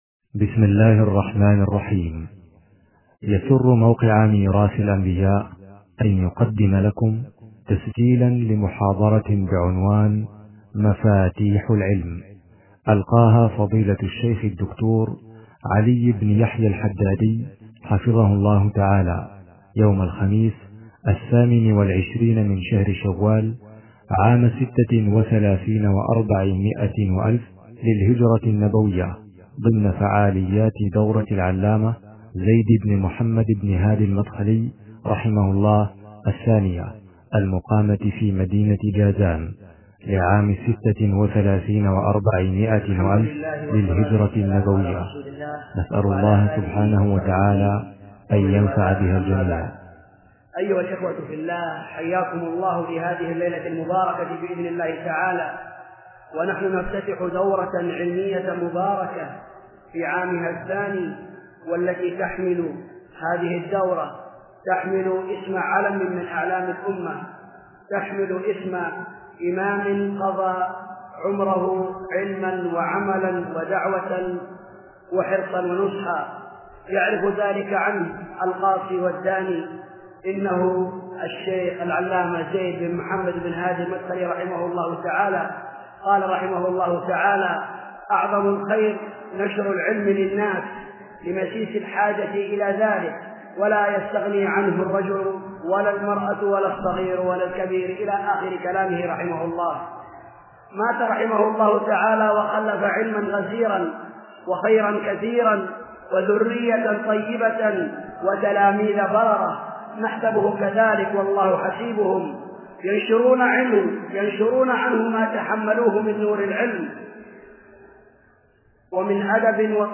المحاضرات